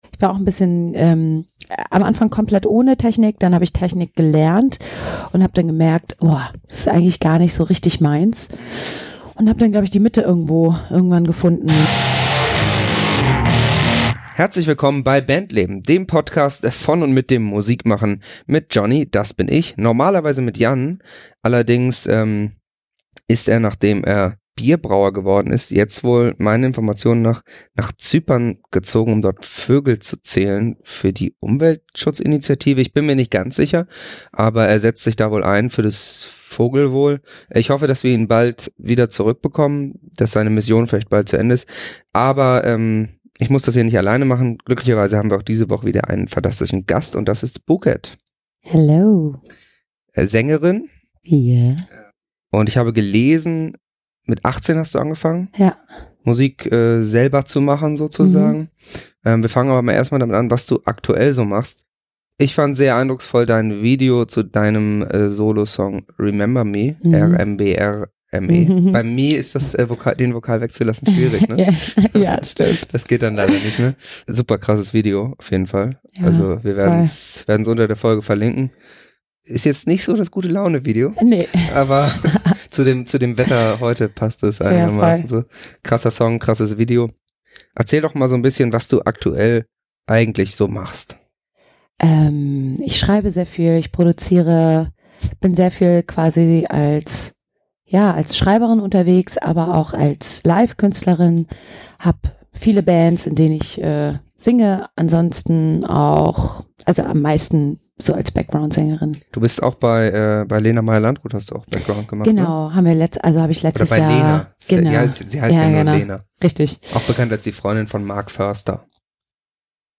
zum Gespräch getroffen(!?)